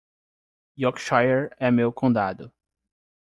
Pronounced as (IPA) /kõˈda.du/